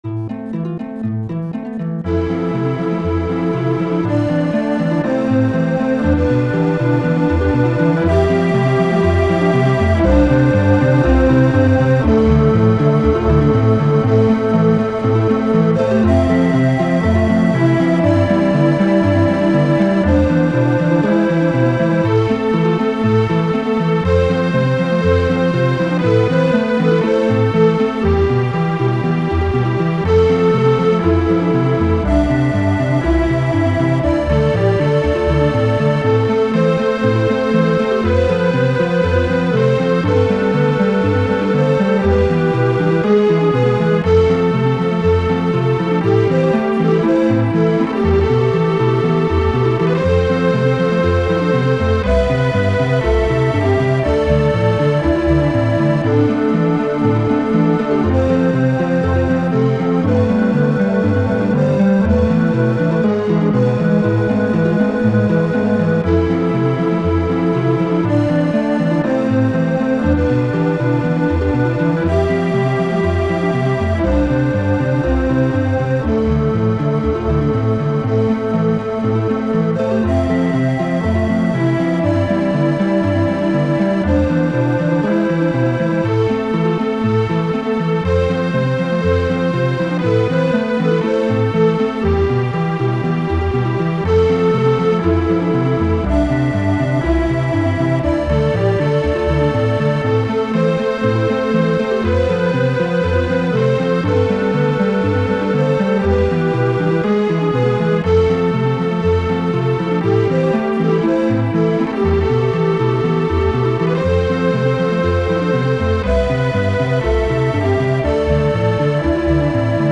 High-quality mp3s that are identical to the game's music.